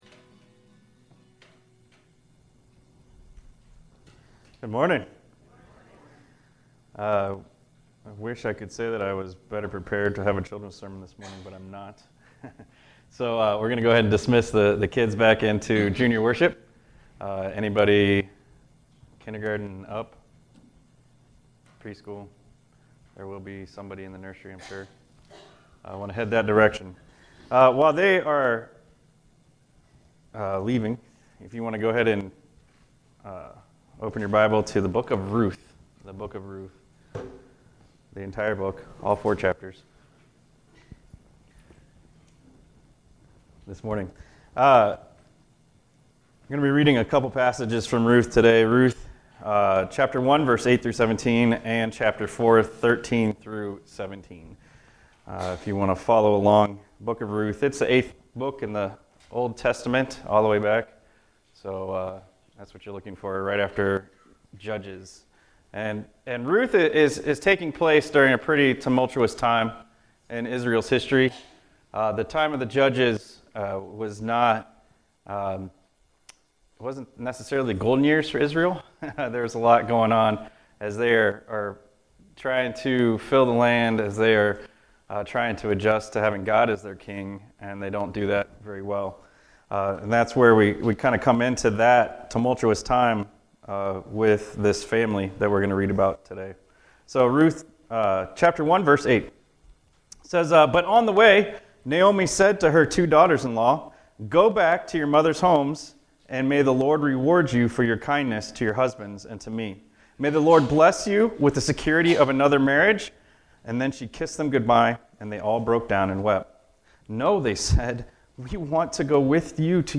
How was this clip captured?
SUNDAY LESSON, 5/19/23 (MOTHER’S DAY)